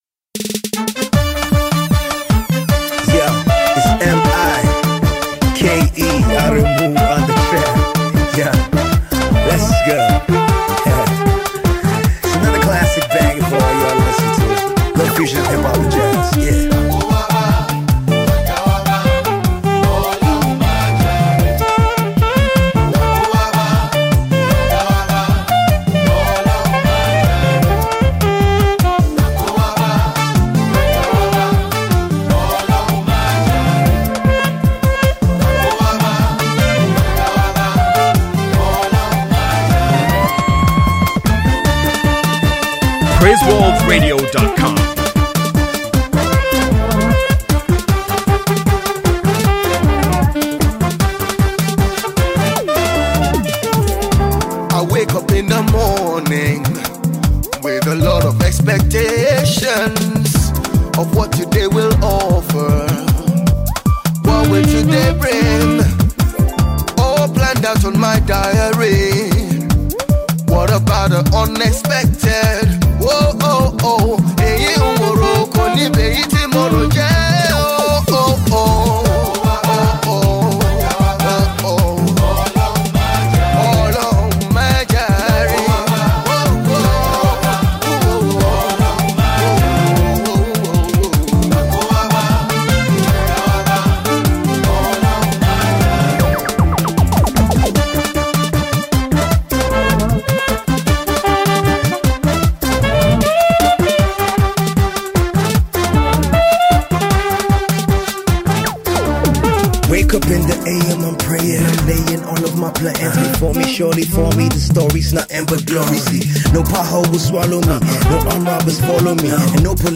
Jazz
a lovely hip hop jazz tone